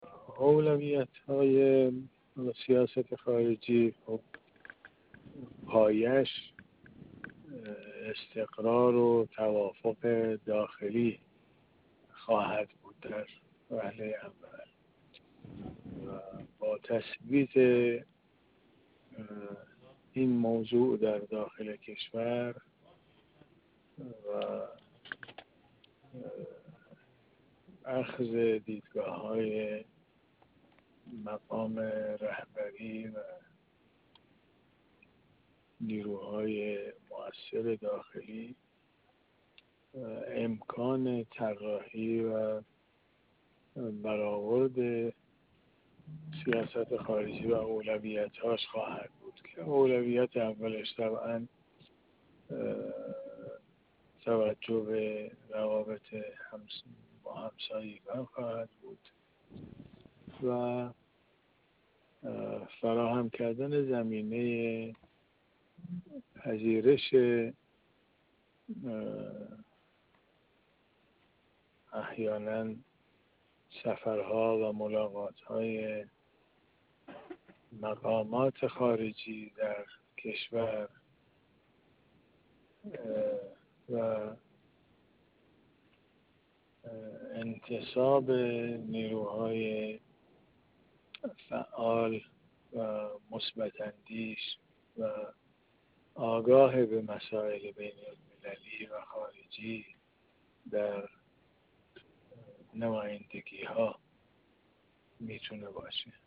در گفت‌وگو با ایکنا درباره اولویت سیاست خارجی در دولت چهاردهم